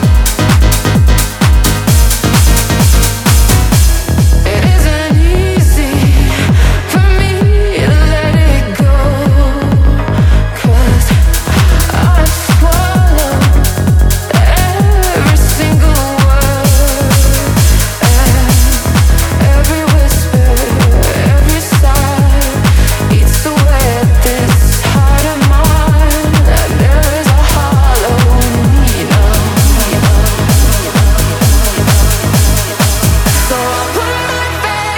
Жанр: Танцевальные
Dance